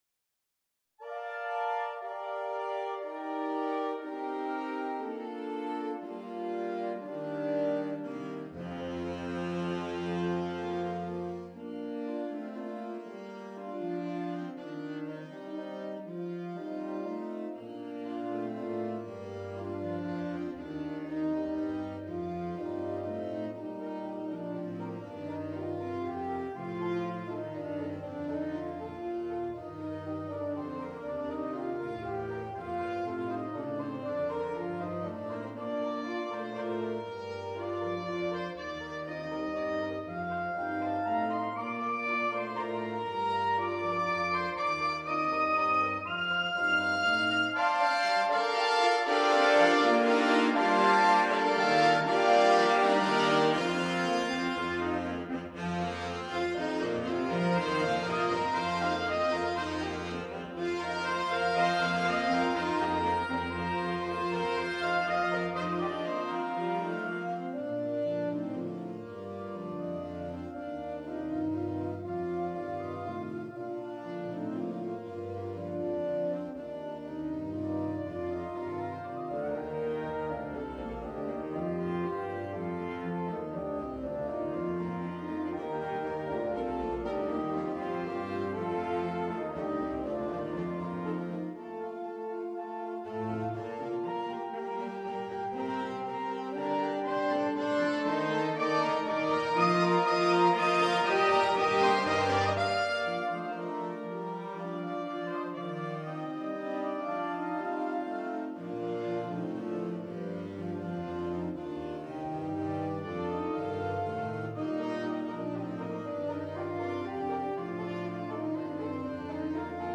Sno/S . S . S/A . AAA . A/T . TT . BB . B/Bs . 2x Percussion
Exported from the Sibelius score using NotePerformer.